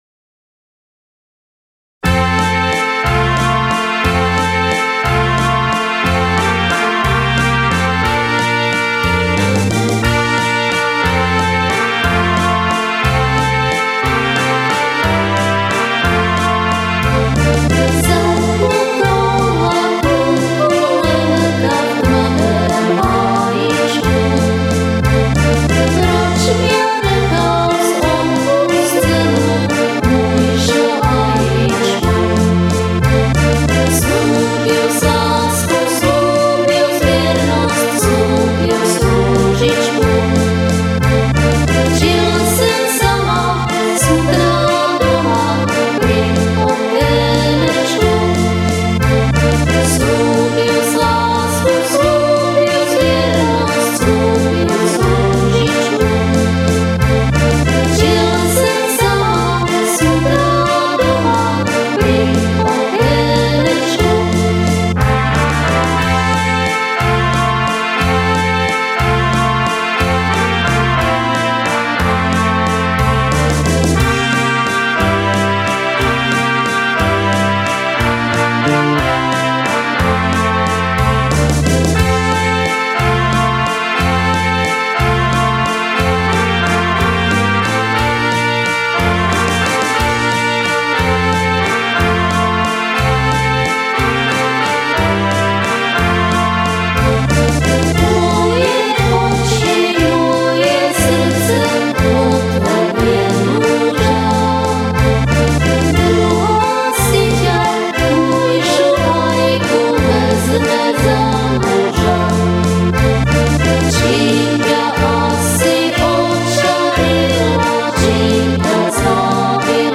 CD2 - Prvá pieseň ktorú naspievala moja lepšia polovička, trošku tréma ale inak fajn...